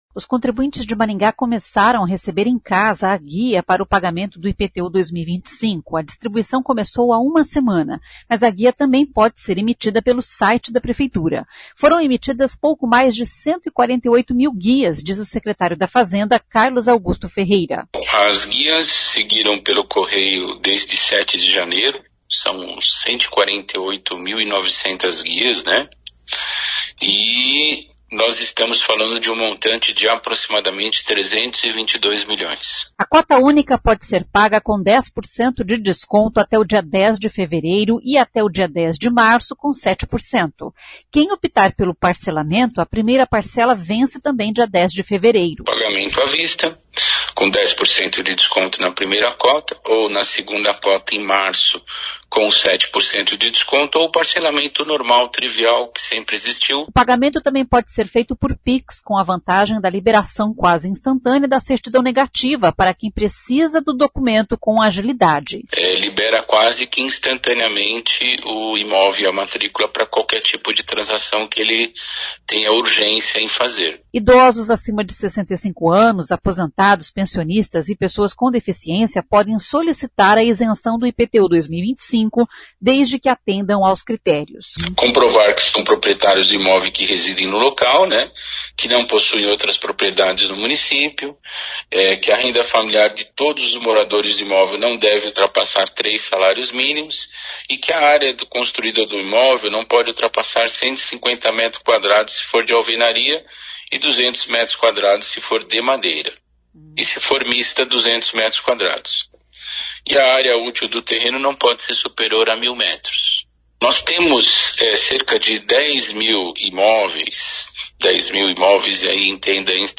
Foram emitidas pouco mais de 148 mil guias, diz o secretário da Fazenda, Carlos Augusto Ferreira.